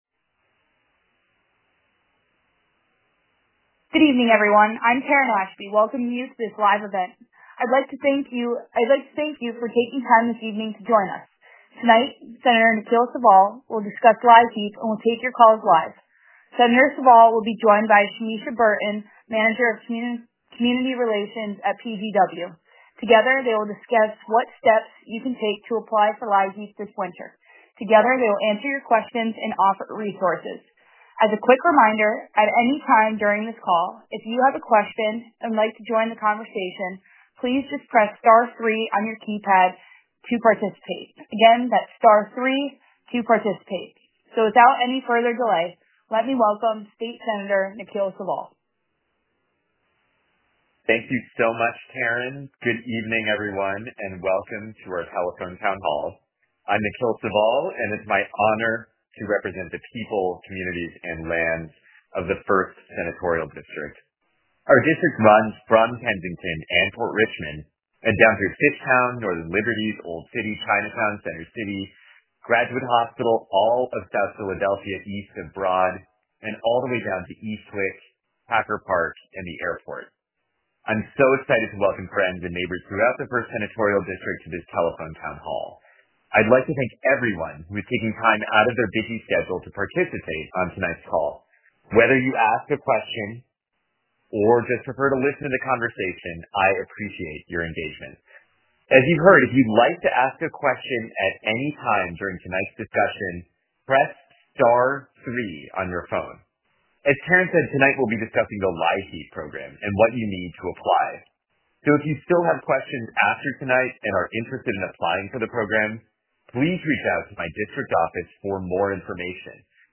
Telephone Town Hall